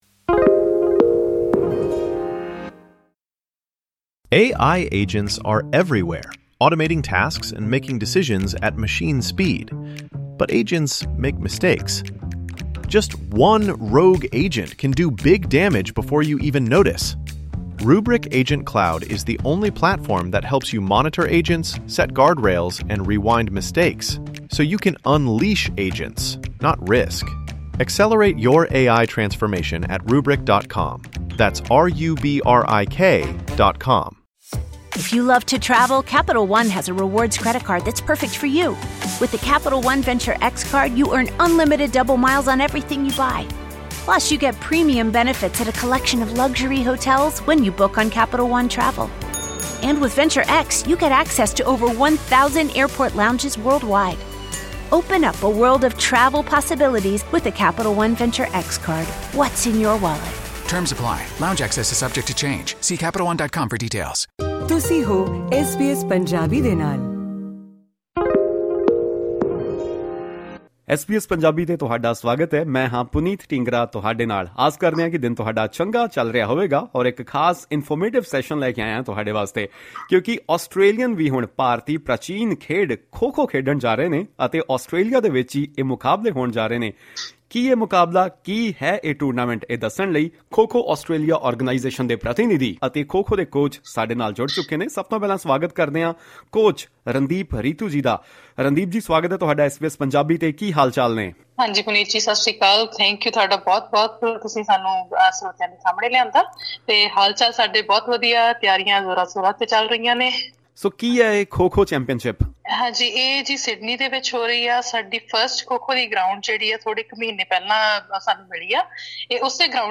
Australia to host its first National Kho-Kho championship — Exclusive insights from Coach and committee members.